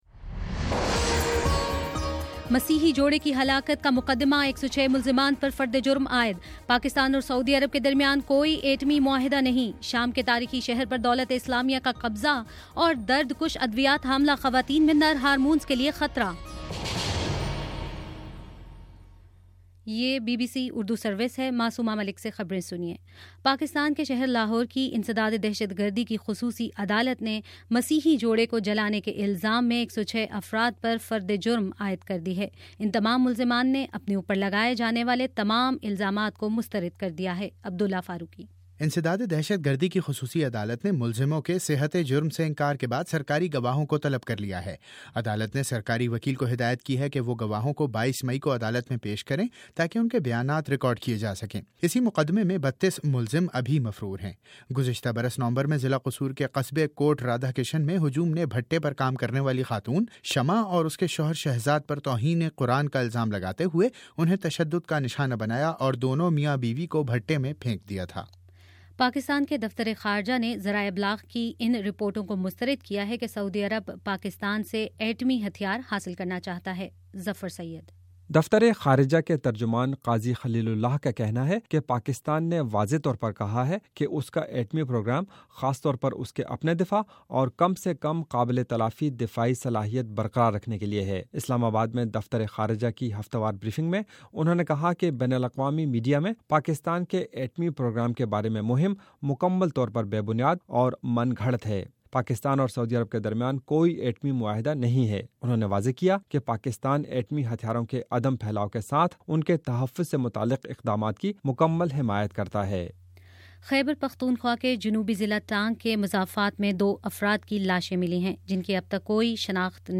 مئی 21: شام پانچ بجے کا نیوز بُلیٹن